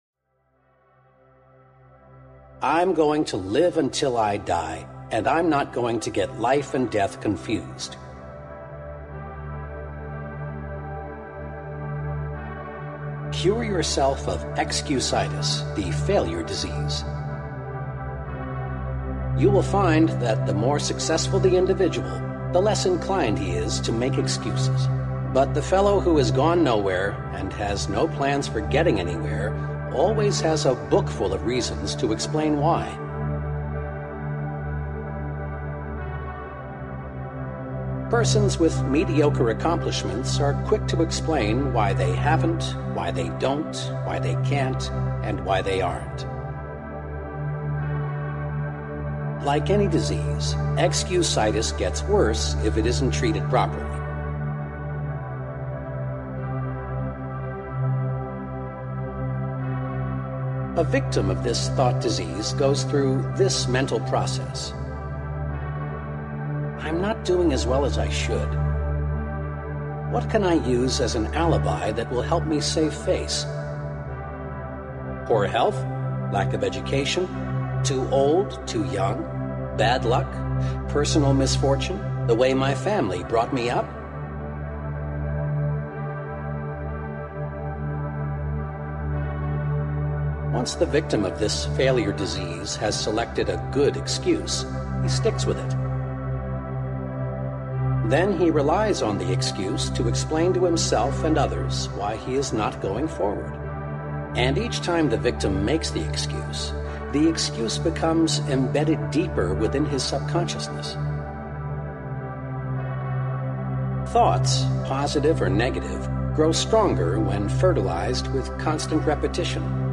All ads in Quote of Motivation begin right at the start of each episode so nothing interrupts the moment you settle in, breathe, and feel that familiar spark rising.